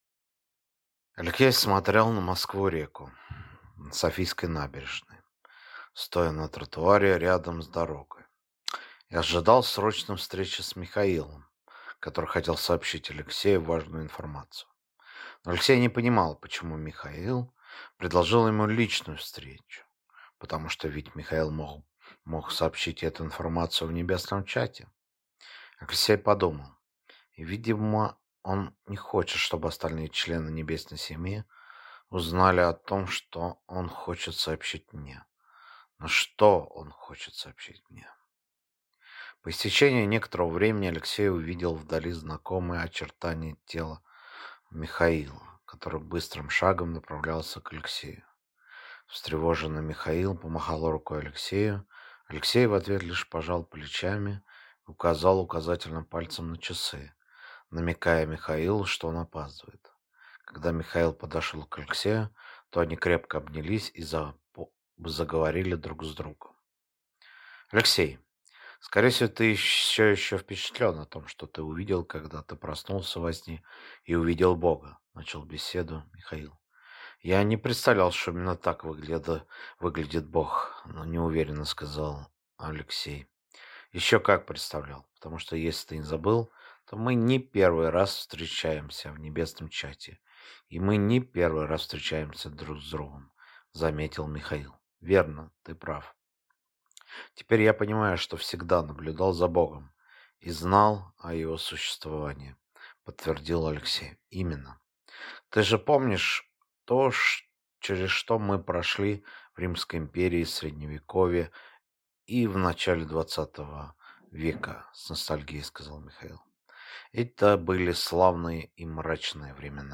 Аудиокнига Демоническая сеть | Библиотека аудиокниг